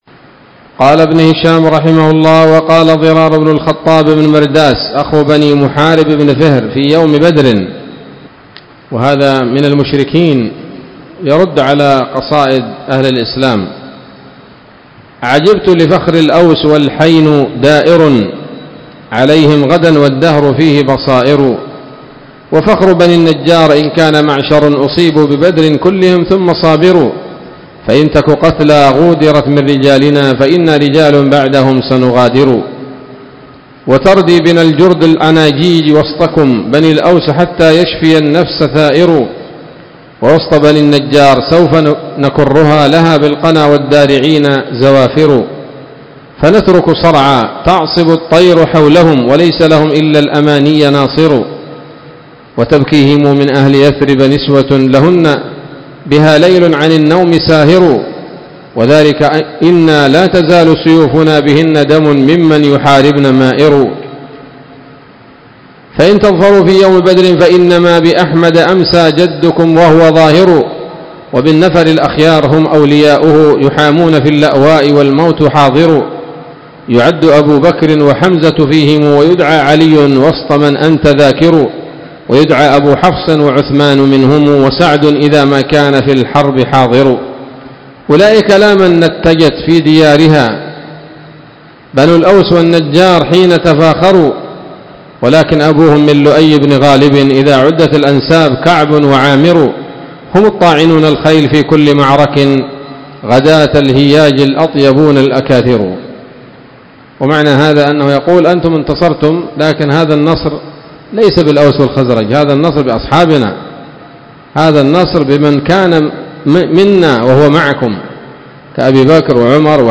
الدرس الثاني والأربعون بعد المائة من التعليق على كتاب السيرة النبوية لابن هشام